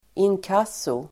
Uttal: [²'in:kas:o]